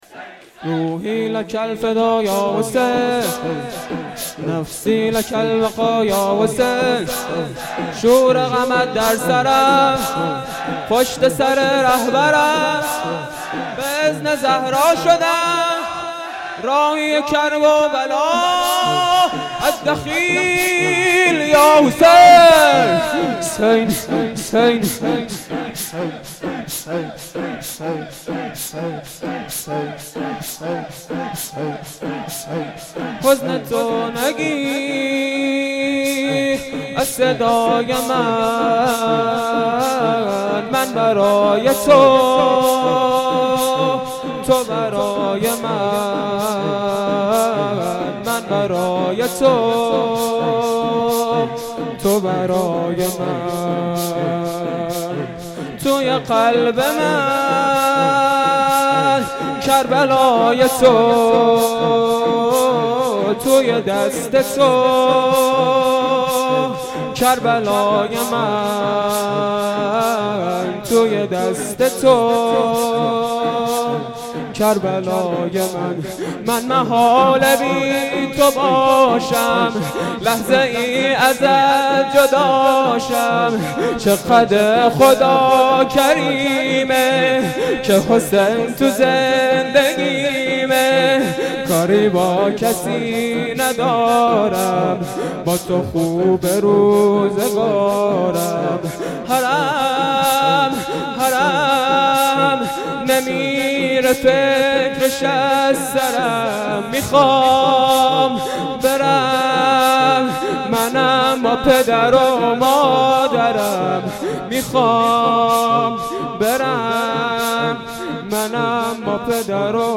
شور سه شب دوم فاطمیه
مداحی
شور3.mp3